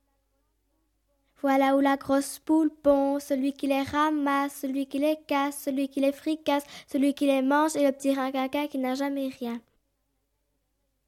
Mode d'expression : chant
Type : enfantine